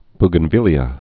(bgən-vĭlē-ə, -vĭlyə, -vēə, bō-)